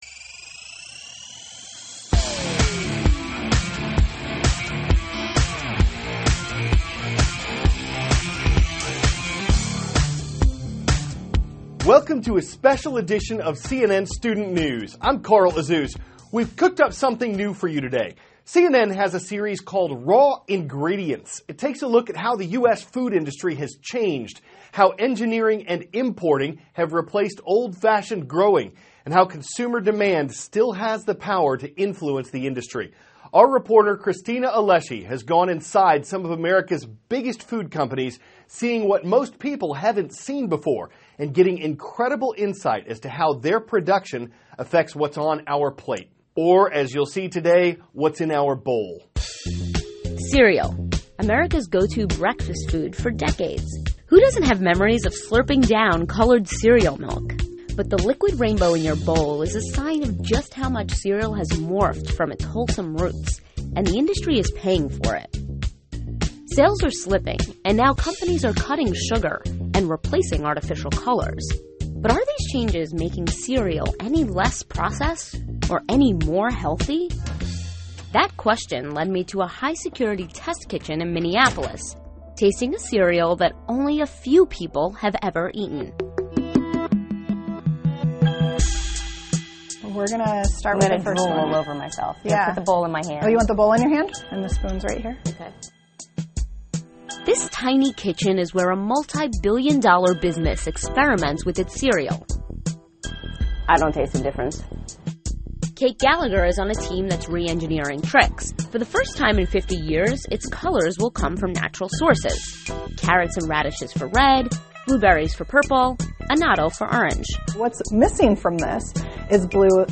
(cnn Student News) -- February 8, 2016 Raw Ingredients: Cereal. Aired 4-4:10a ET THIS IS A RUSH TRANSCRIPT.